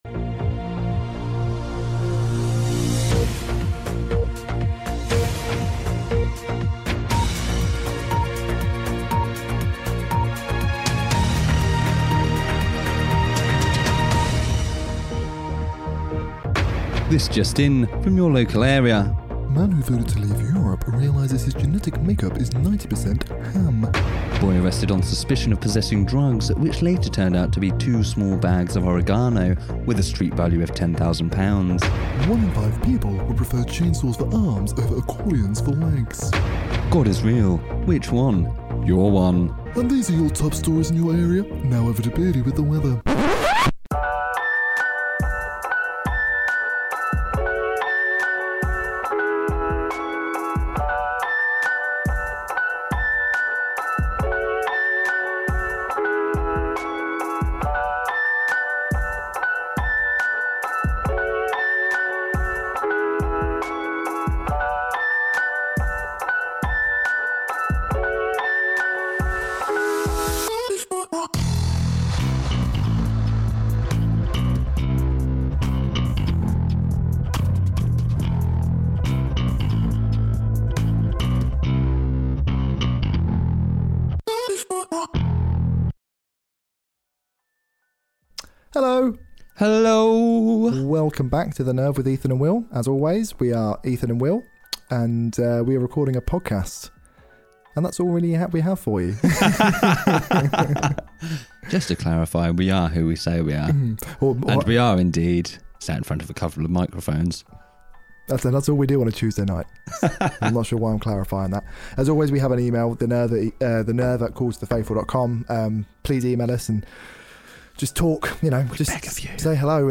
We have another nonsense filled chat for you and this week:
This podcast is full of swearing so if that offends you, this podcast isn't right for you!